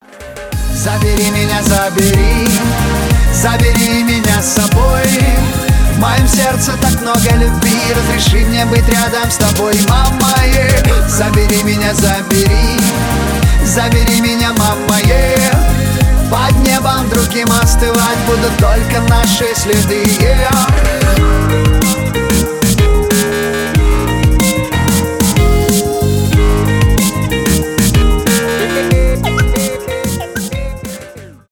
поп , регги
рэп
дабстеп